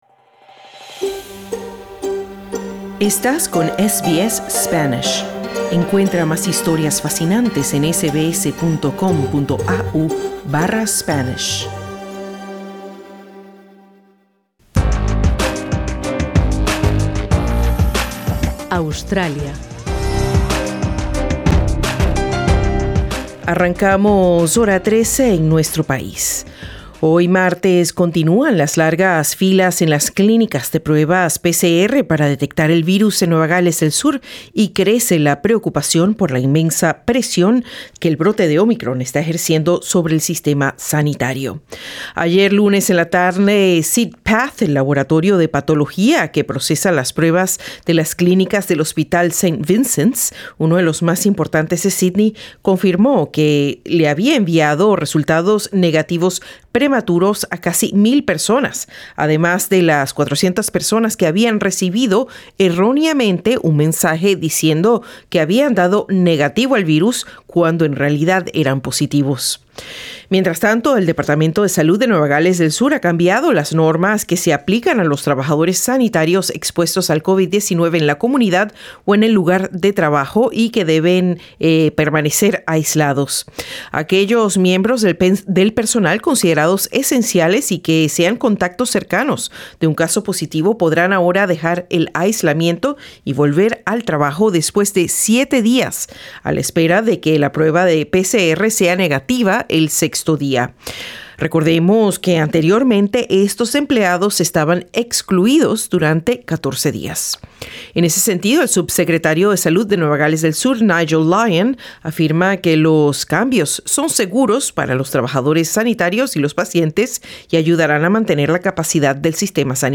El Departamento de Salud de Nueva Gales del Sur ha recortado de 14 a siete días el período de aislamiento para los trabajadores sanitarios que hayan estado expuestos al COVID-19, con el fin de aliviar la presión al sistema de salud. Trabajadores sanitarios hispanos cuentan a SBS Spanish que la información que llega del gobierno es confusa, en momentos en que el personal en muchos centros médicos se encuentran exhaustos, y no se dan abasto.